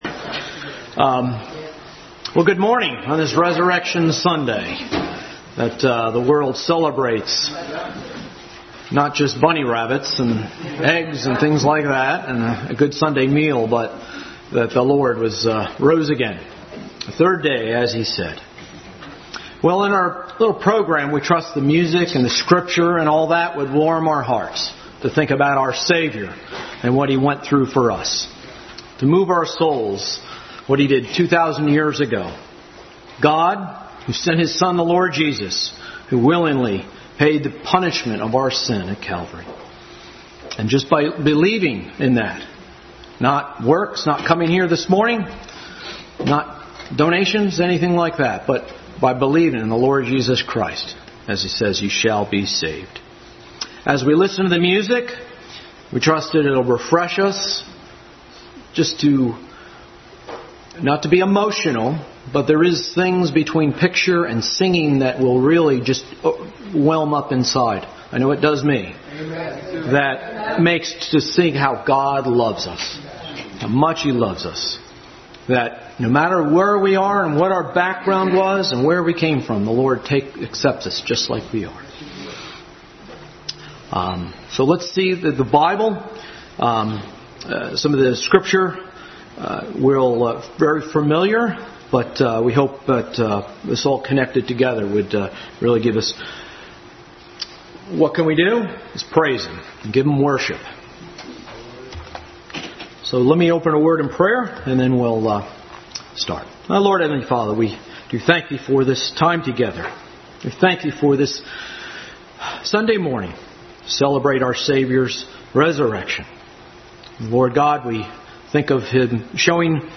Easter Service 2021